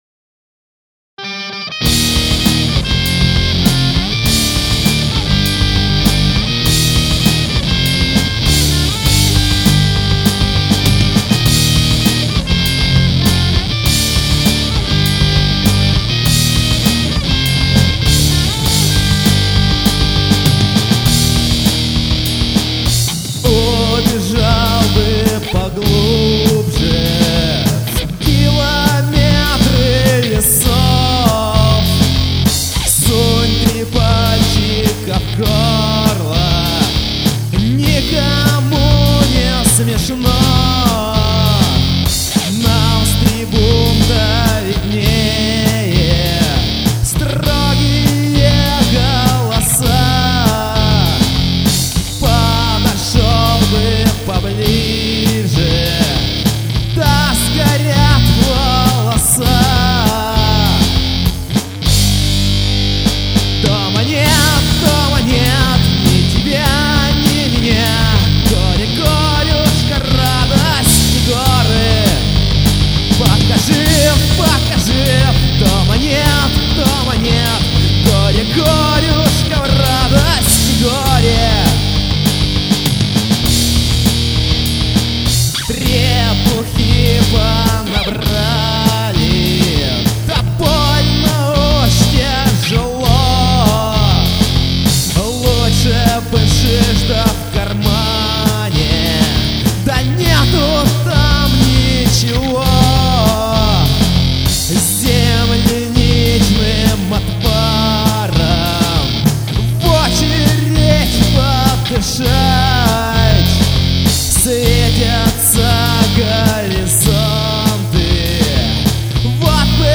Музыкальный хостинг: /Панк